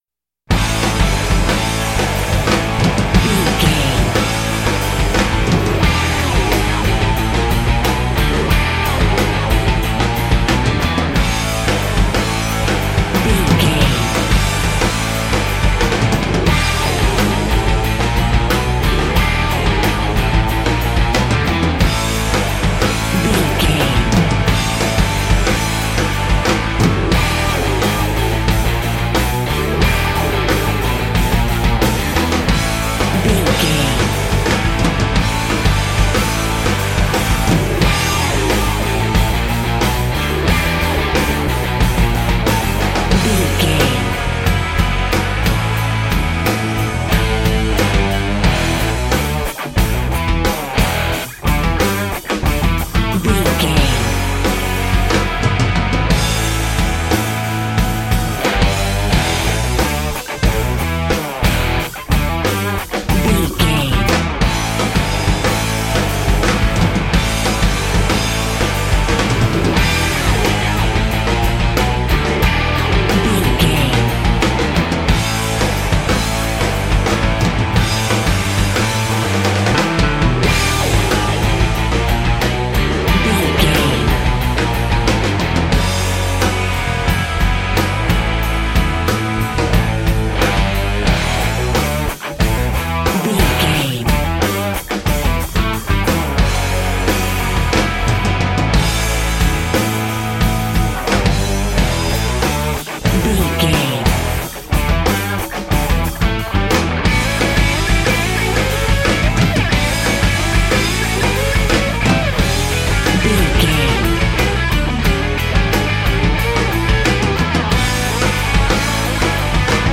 Ionian/Major
electric guitar
synthesiser
drums
bass guitar
hard rock
aggressive
energetic
intense
nu metal
alternative metal